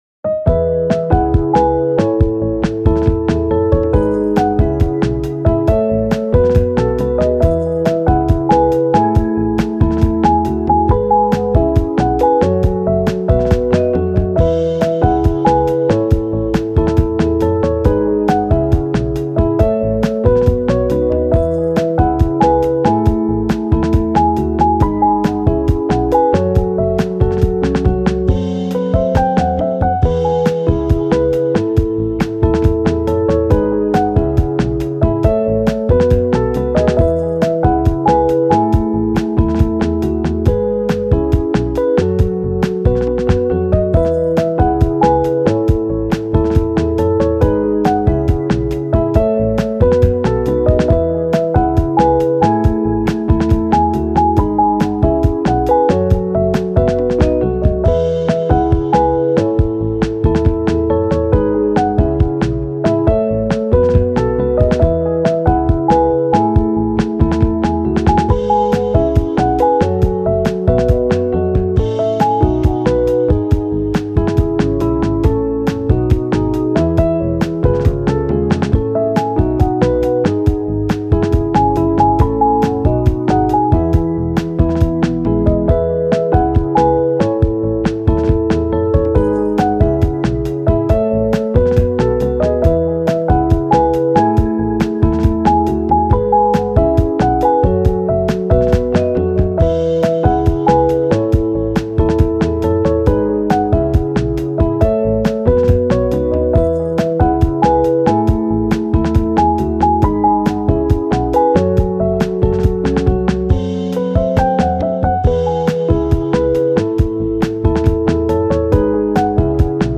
明るい・ポップ